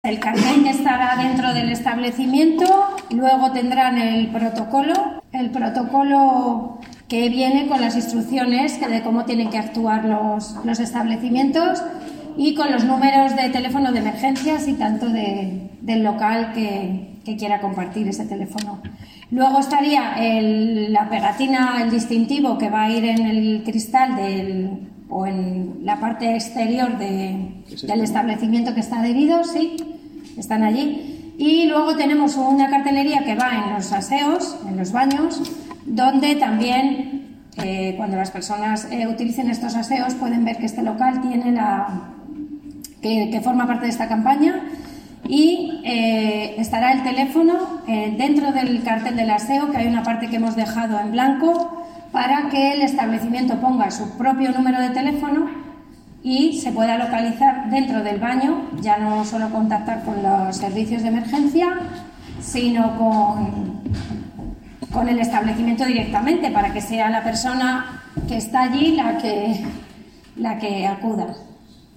Declaraciones de la concejala de Igualdad, Piedad Agudo